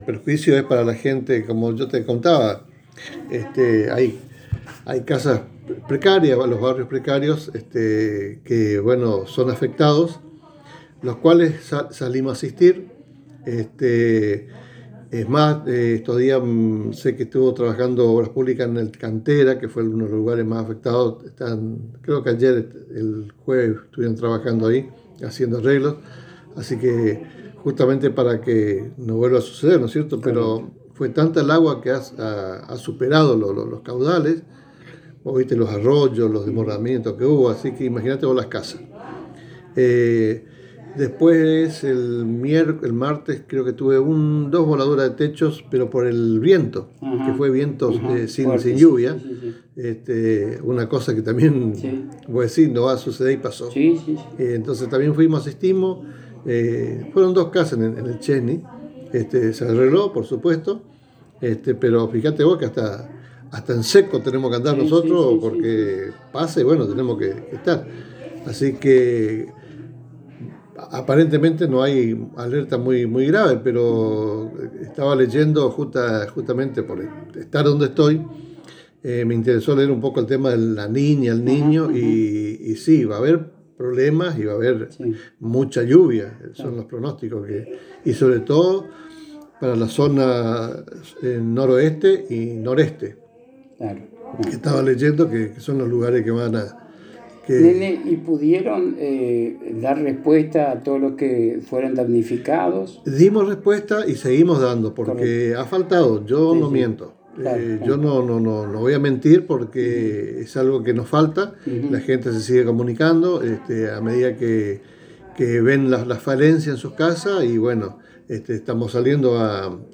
En diálogo exclusivo con la ANG el Secretario de Desarrollo Social de Apóstoles Alberto «Nene» Sotelo manifestó que aún hoy se continúa asistiendo a las familias damnificadas por el temporal ocurrido días atrás y están atentos y listos por las futuras condiciones climáticas.